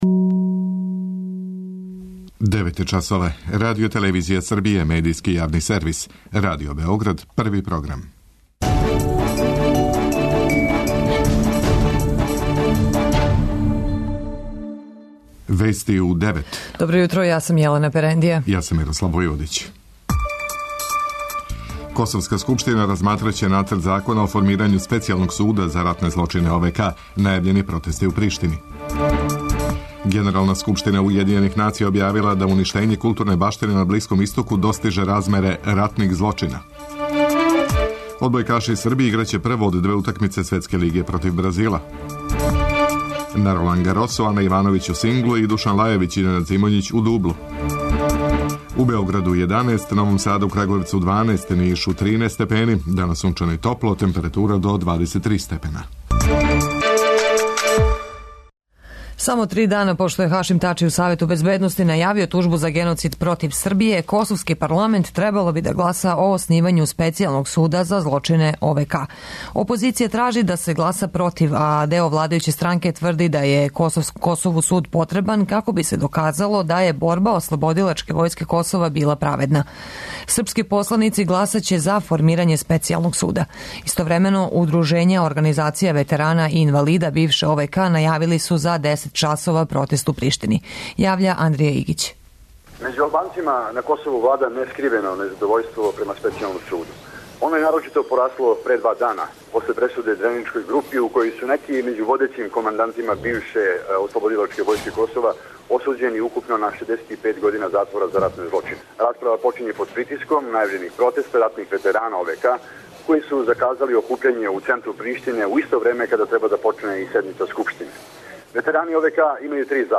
преузми : 10.17 MB Вести у 9 Autor: разни аутори Преглед најважнијиx информација из земље из света.